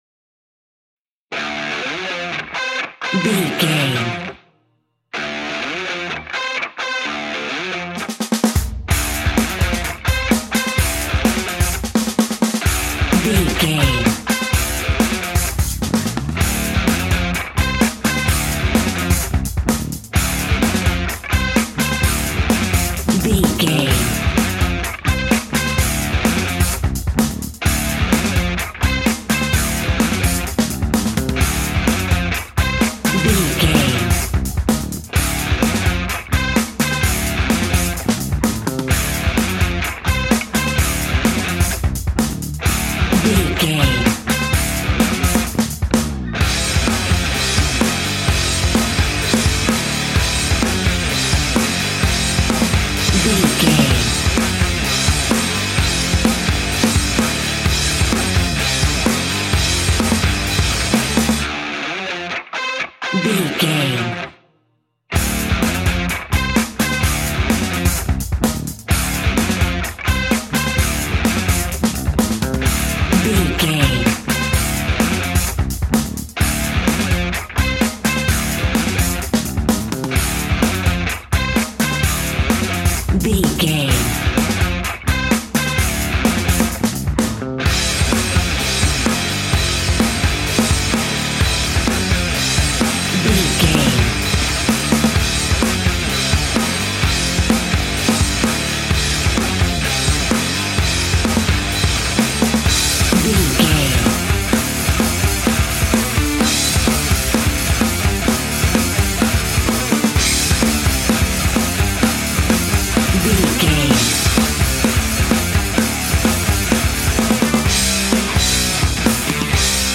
Aeolian/Minor
D
hard rock
heavy metal
dirty rock
scary rock
rock instrumentals
Heavy Metal Guitars
Metal Drums
Heavy Bass Guitars